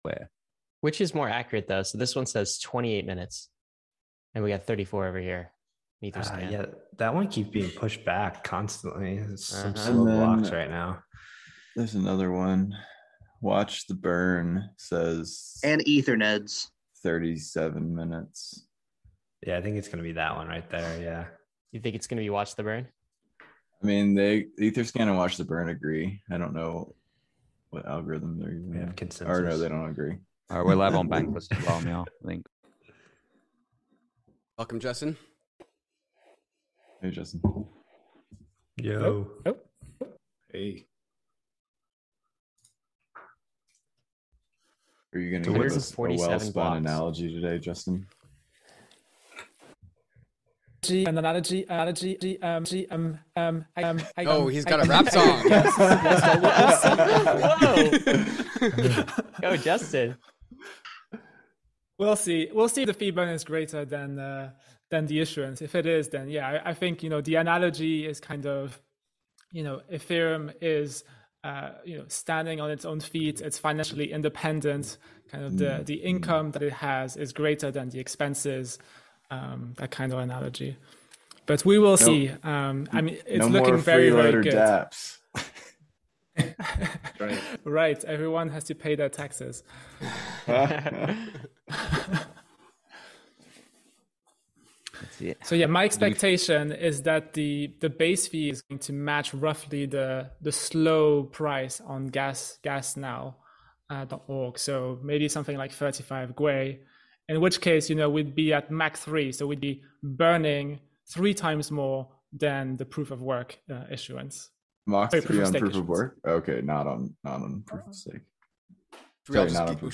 LIVESTREAM: London Hardfork | EIP1559 GOES LIVE